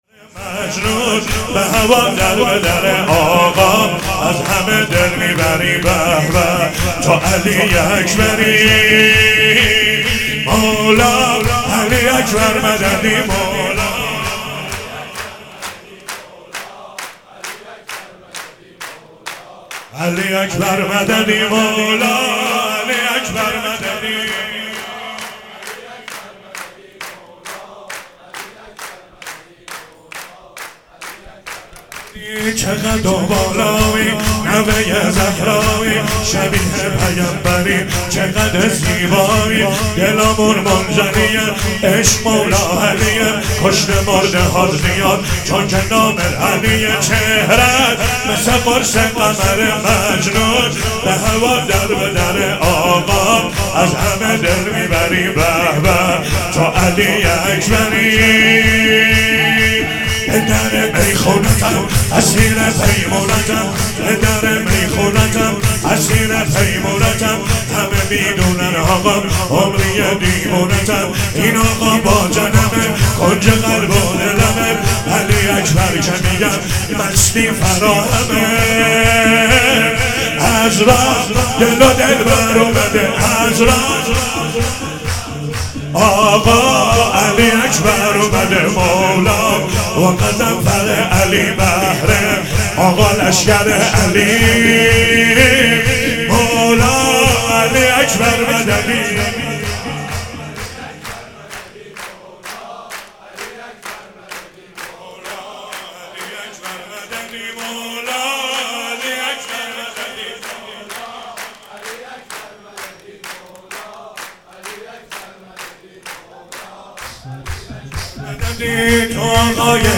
چهاراه شهید شیرودی حسینیه حضرت زینب (سلام الله علیها)
شور- چه قد و بالایی